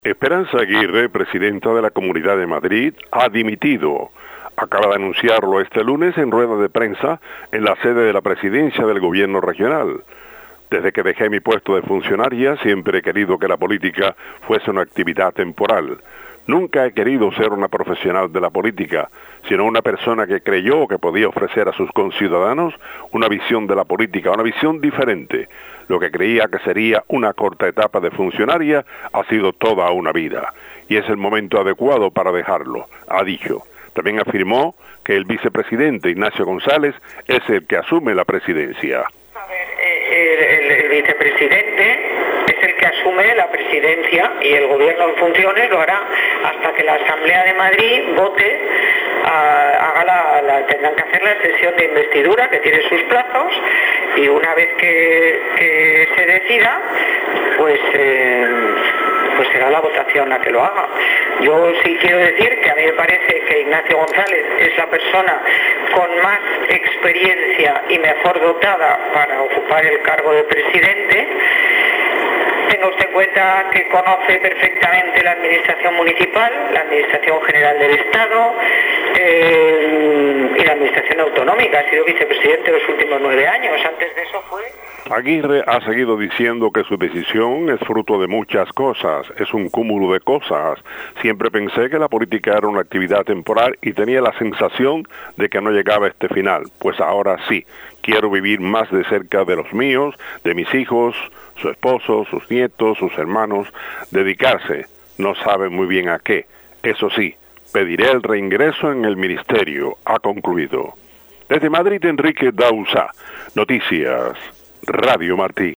Renuncia a su cargo la presidenta de la Comunidad de Madrid, Esperanza Aguirre. Con el informe desde España